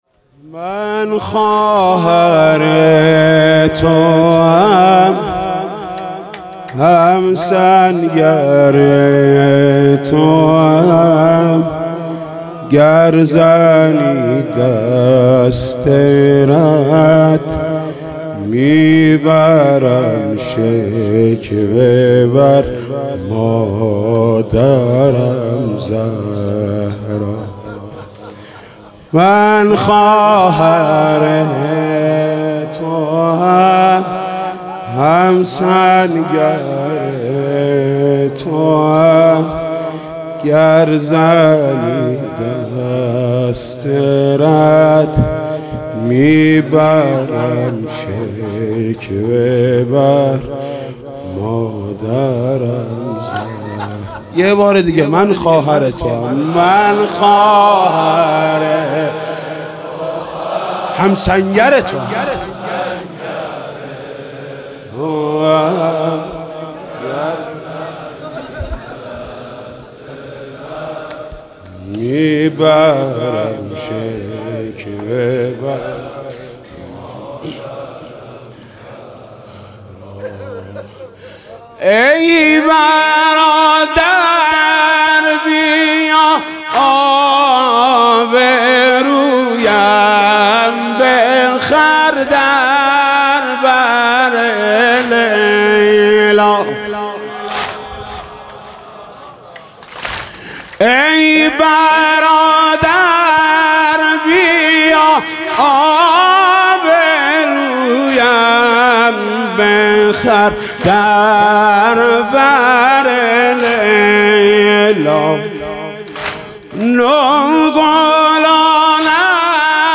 خیمه گاه - شب چهارم محرم - دوطفلان حضرت زینب سلام الله علیها - محرم96 - واحد - من خواهر توام همسنگر توام